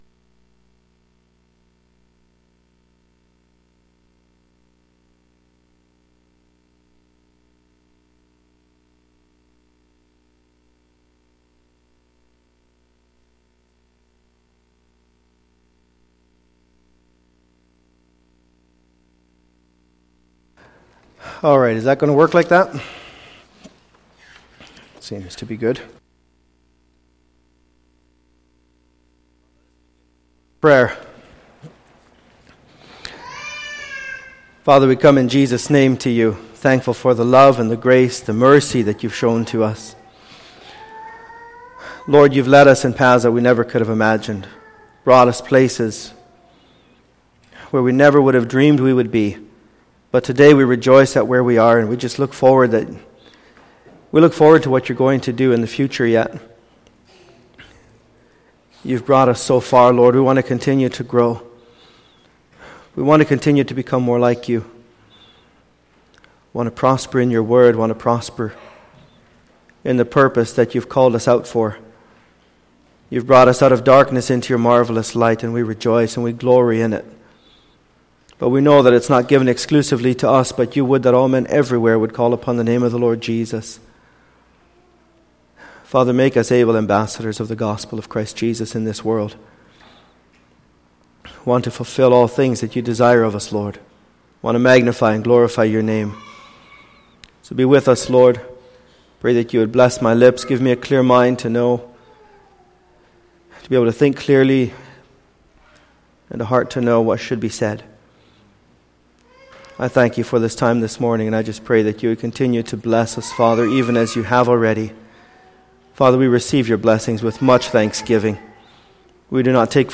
Bible Teaching Service Type: Sunday Morning %todo_render% « Friday Evening Ordanation Message Ordination Message